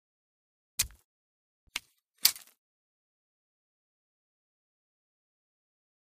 Stab Flesh
Knife Into Flesh: In / Twist / Out; Quick, Juicy, Light Body Stab And Knife Pull Out. Medium Perspective.